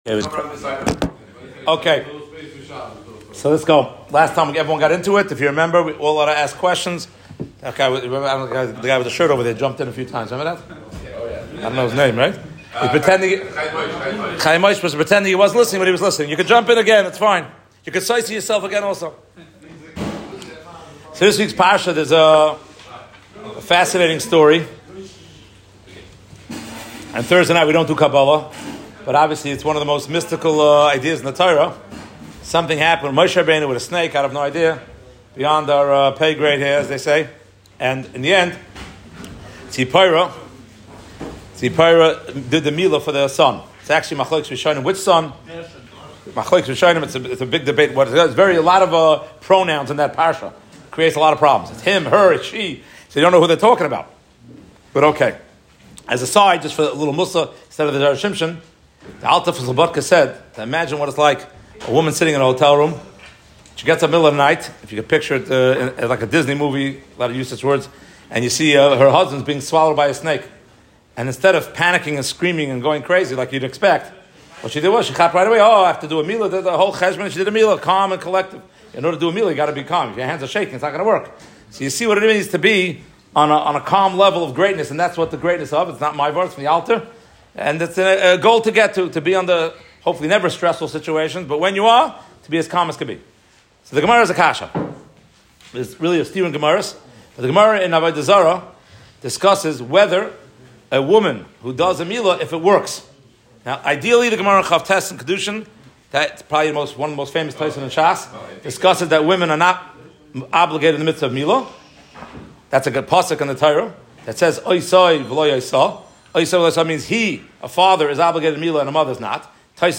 Bnei Keturah are obligated in milah what about the bnos Keturah? Tziporah the mohel was because she was a Jewish woman or Midyanite woman from Keturah? At Sasregan